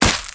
Sfx_creature_penguin_hop_land_04.ogg